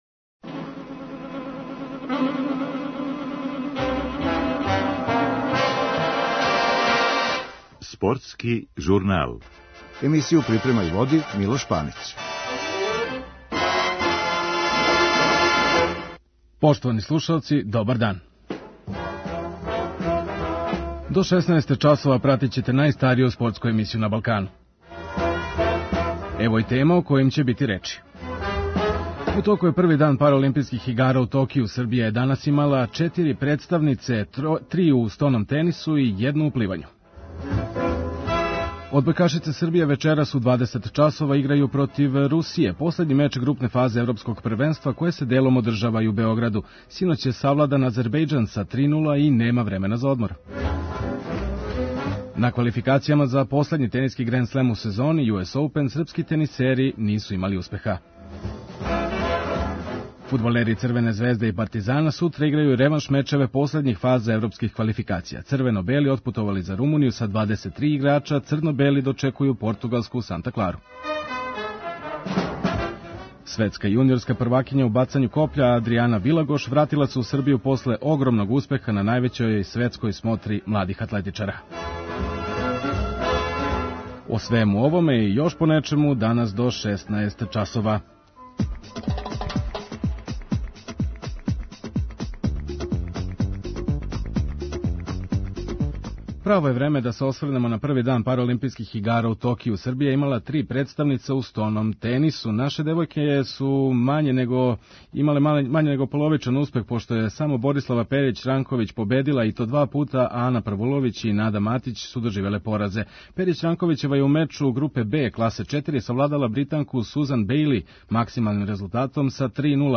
Током емисије, чућете изјаве наших одбојкашица после четврте победе на првенству, а вечерашњи спектакл Србија - Русија, слушаоци нашег радија имаће прилику да прате кроз укључења од 20 часова.